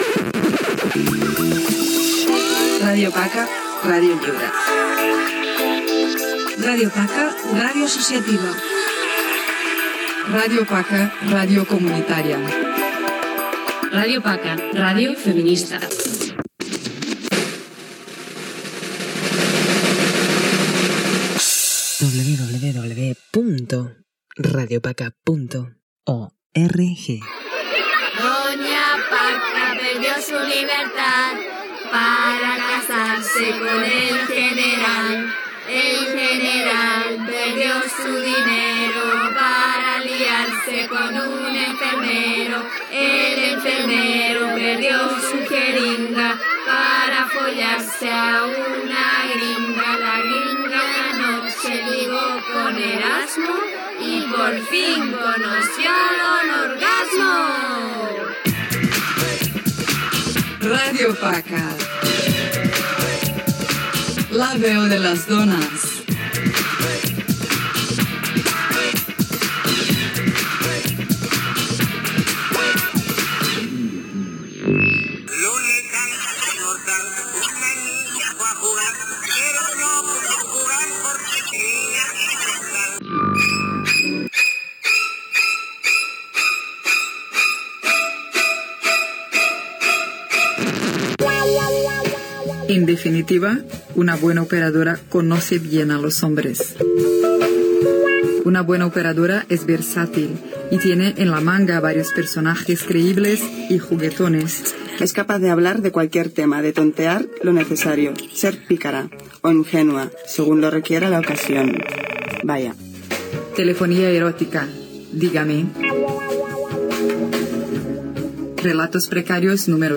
Indicatiu de la ràdio, adreça a Internet, cançó satírica, indicatiu, les qualitats d'una bona operadora telefònica, indicatiu, les dones protesten per la feina domèstica que han de fer, invitació a participar a la ràdio, tema musical, connecta amb Ràdio Paca i les dones que la fan, indicatiu d'"Hem pres la ràdio"
Entreteniment